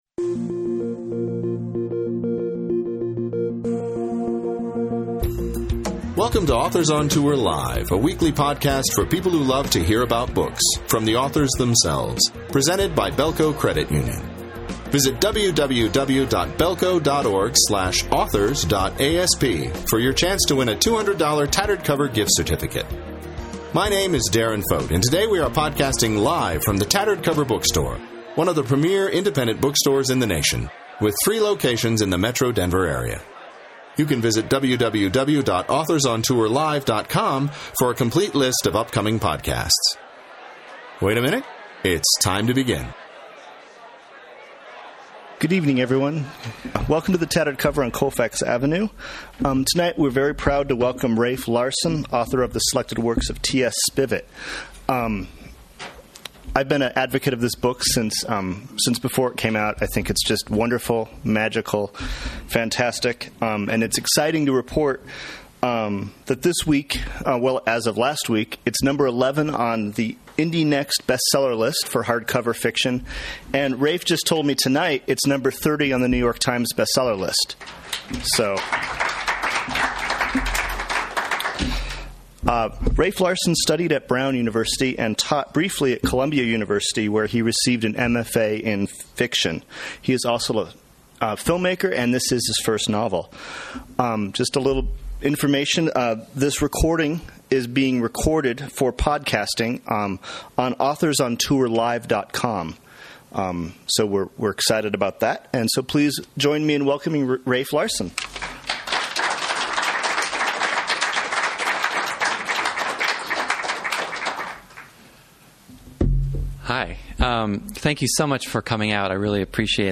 Filed under Book Store Events